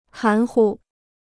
含糊 (hán hu) Kostenloses Chinesisch-Wörterbuch